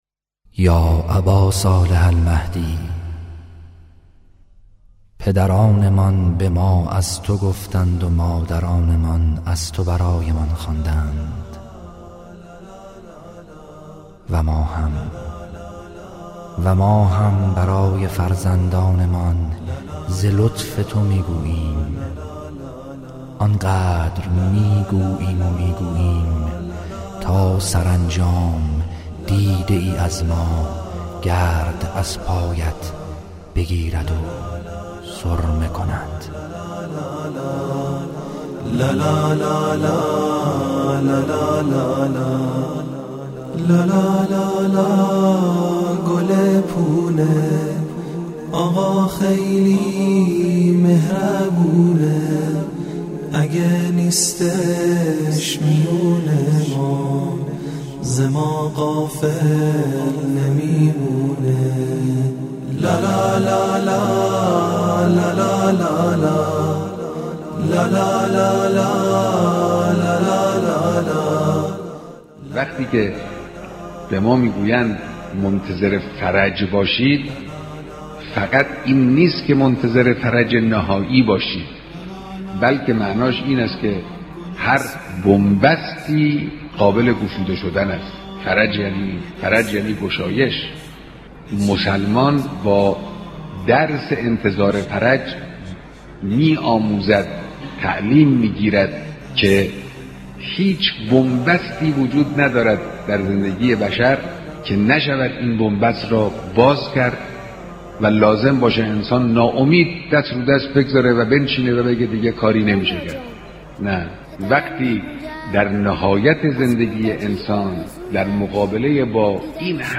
❖ کلیپ های صوتی رهبری با موضوع انتظار و مهدویت ❖